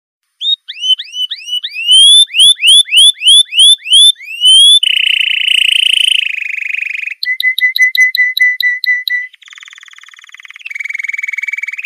Птичий рингтон
Отличного качества, без посторонних шумов.